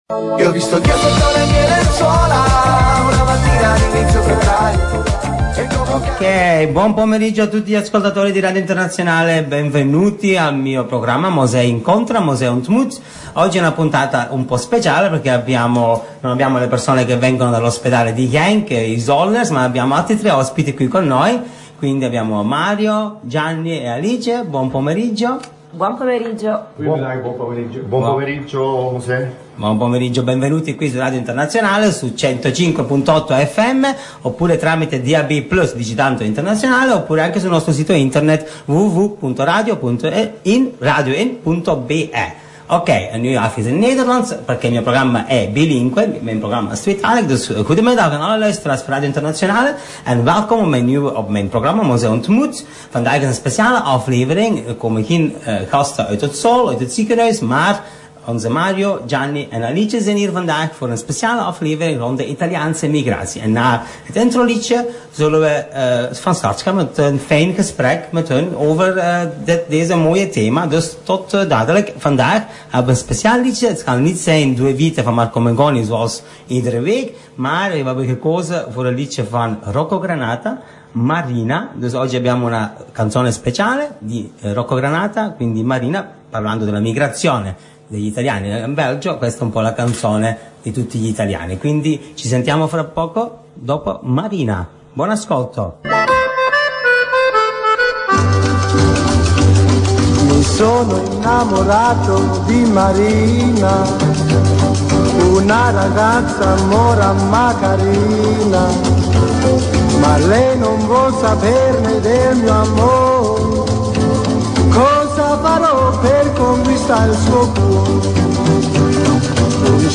Un viaggio tra memoria, musica e testimonianze per raccontare il passato e guardare al futuro della comunità italiana in Belgio.
Ospiti in studio